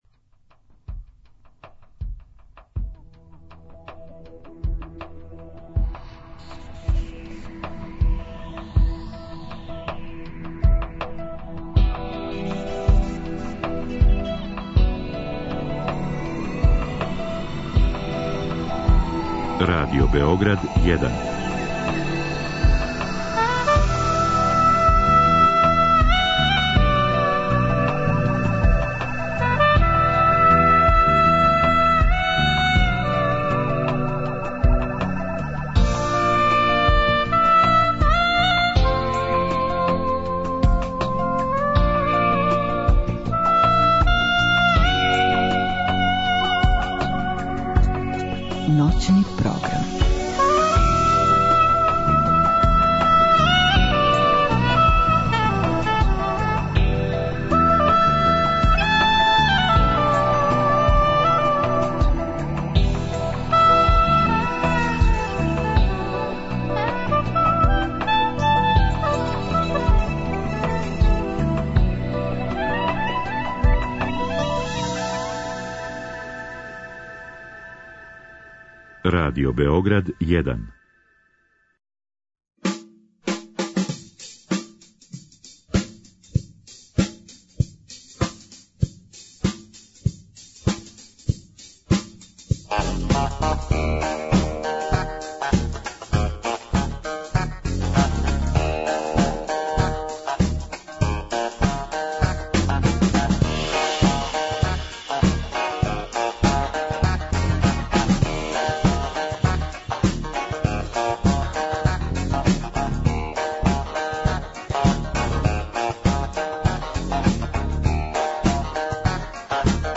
За крај друге сезоне музичке и компјутерске легенде. Гости су: Џинџер Божиновић и Вицко Милатовић – Рибља Чорба